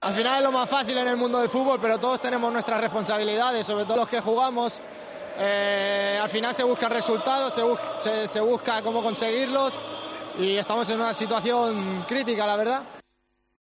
AUDIO: El centrocampista del Barcelona habló tras la dura derrota ante el Benfica en la 2ª jornada de la Liga de Campeones.